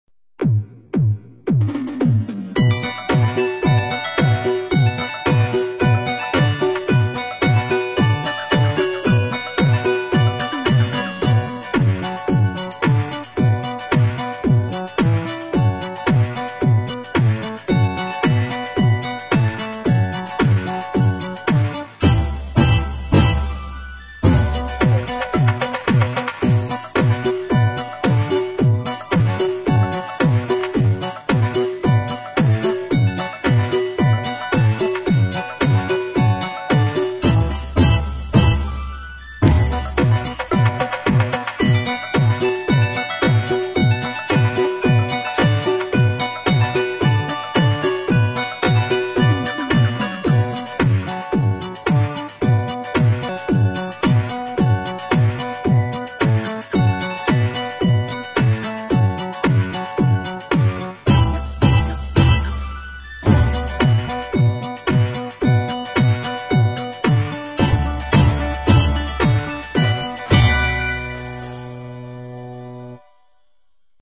无 调式 : D 曲类